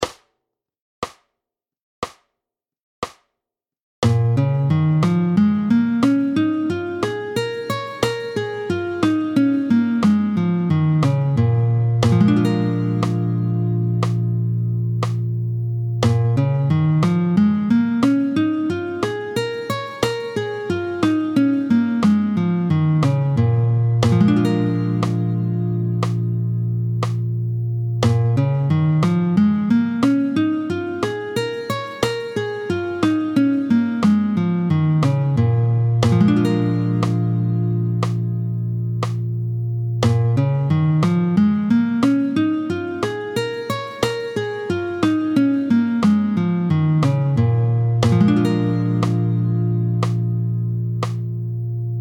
31-01 Doigté 1 en Sib, tempo 60